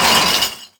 glass_impact.wav